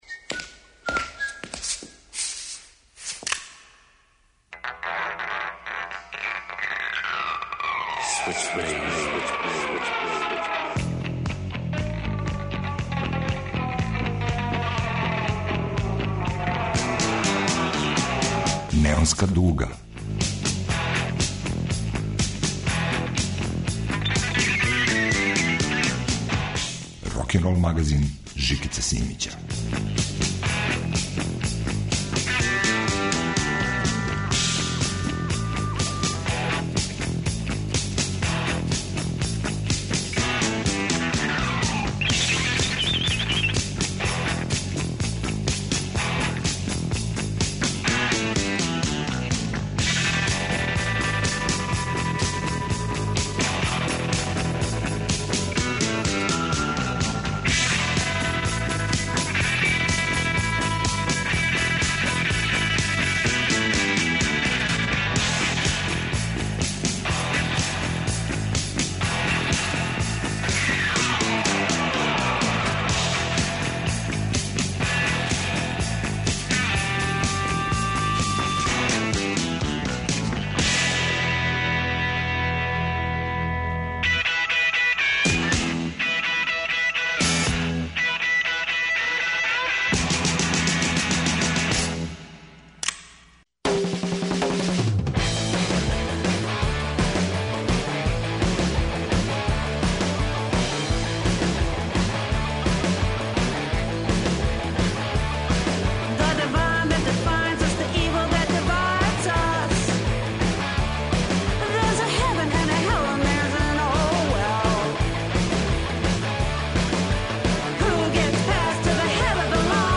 Психоделичне ескападе, исповедне шансоне и генерацијске химне.
Рокенрол као музички скор за живот на дивљој страни. Вратоломни сурф кроз време и жанрове.